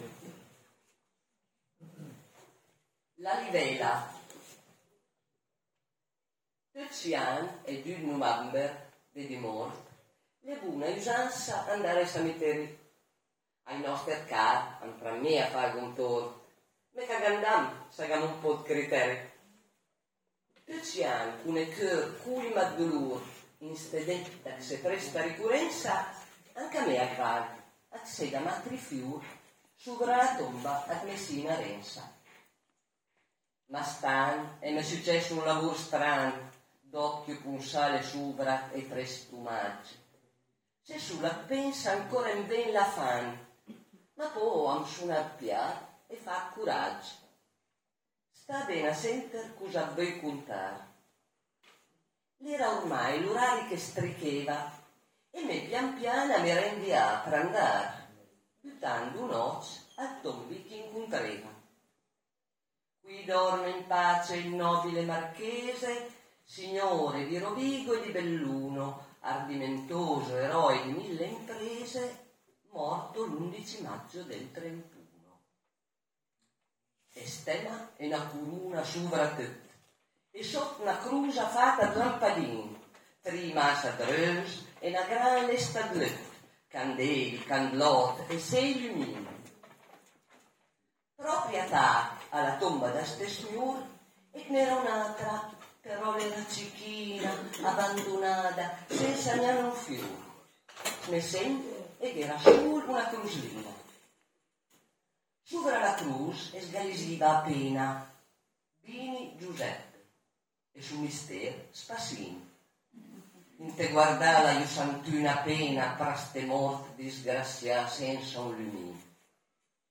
(Dialetto Valestra-Carpineti)